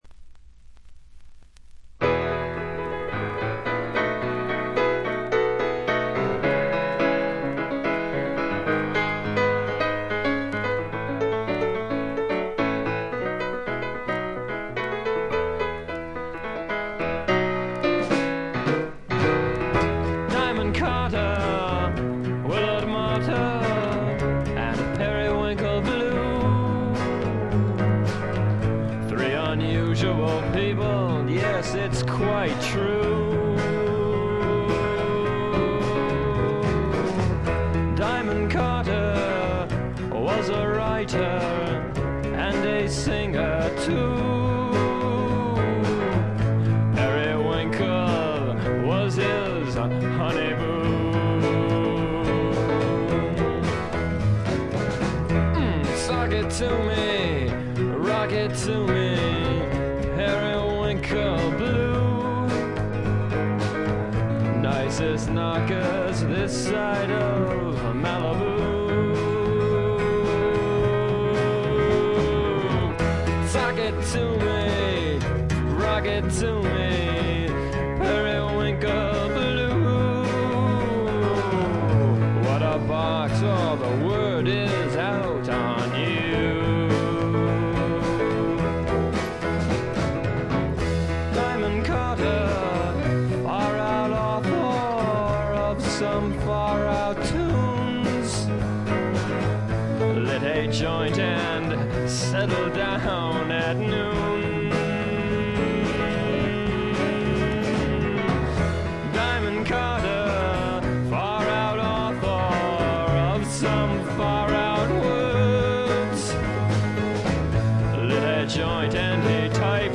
静音部での軽微なバックグラウンドノイズ、ところどころでプツ音が少々聴かれた程度。
試聴曲は現品からの取り込み音源です。